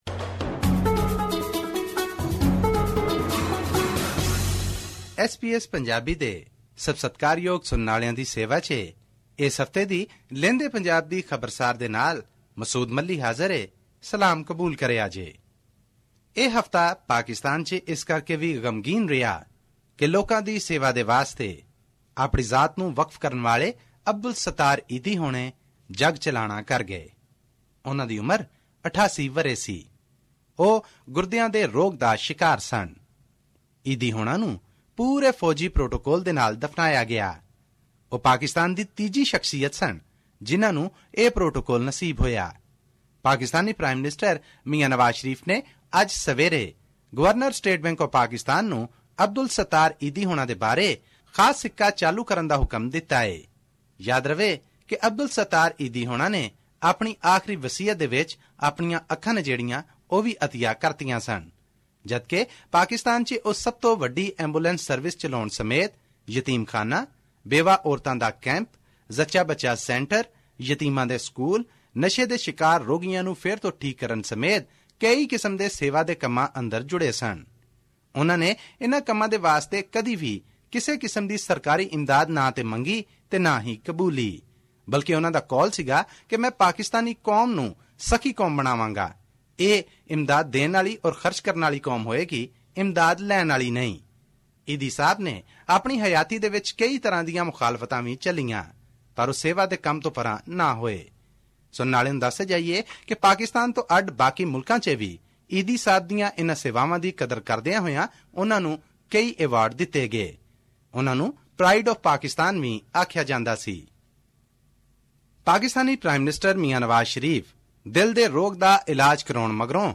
His report was presented on SBS Punjabi program on Tuesday, July 12, 2016, which touched upon issues of Punjab and national significance in Pakistan. Here's the podcast in case you missed hearing it on the radio.